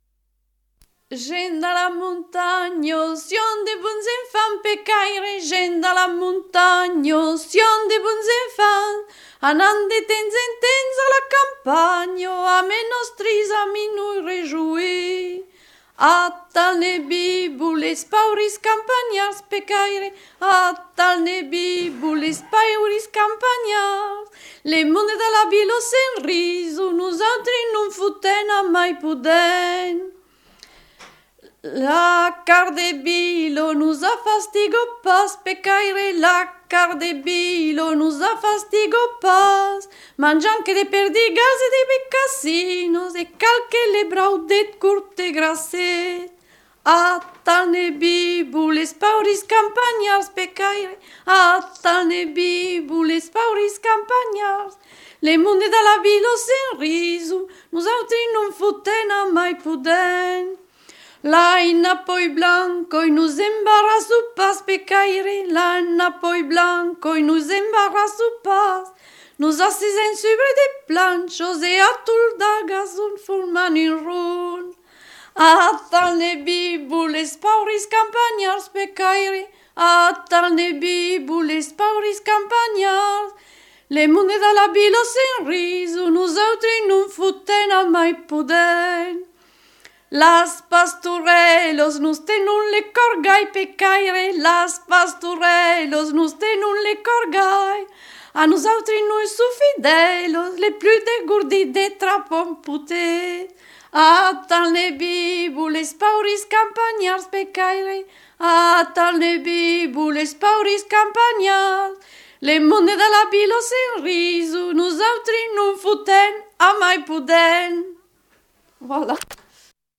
Lieu : Mas-Cabardès
Genre : chant
Effectif : 1
Type de voix : voix de femme
Production du son : chanté
Classification : chanson identitaire